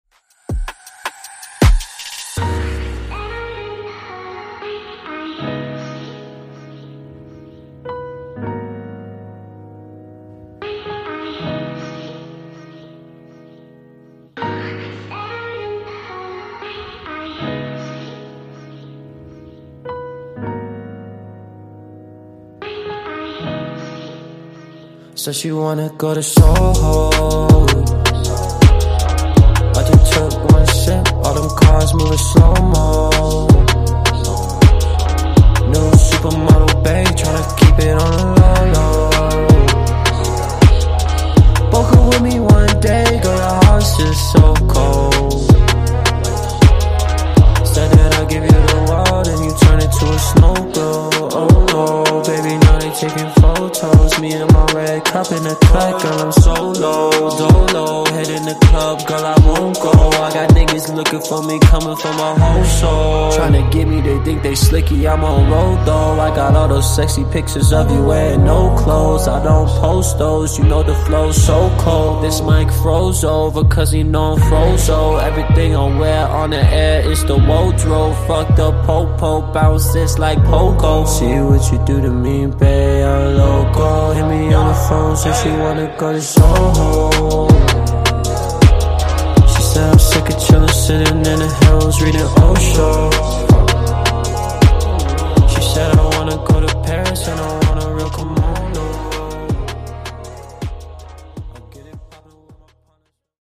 Genres: RE-DRUM , REGGAETON Version: Clean BPM: 120 Time